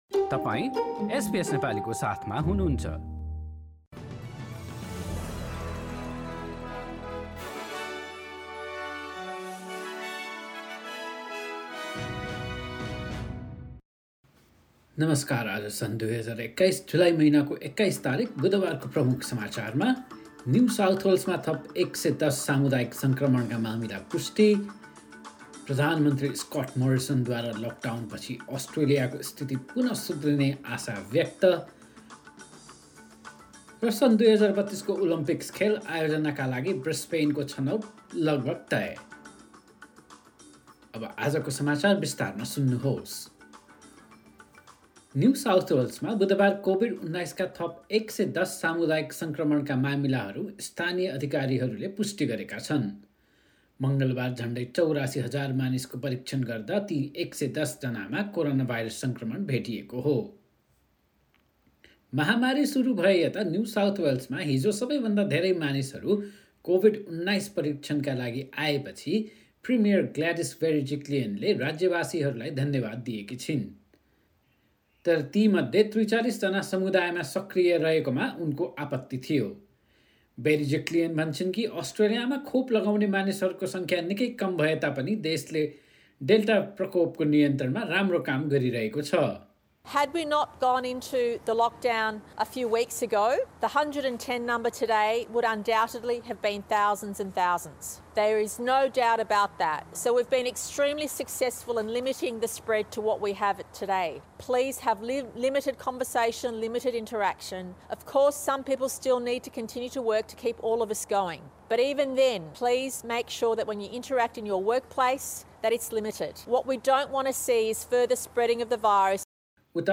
एसबीएस नेपाली अस्ट्रेलिया समाचार: बुधवार २१ जुलाई २०२१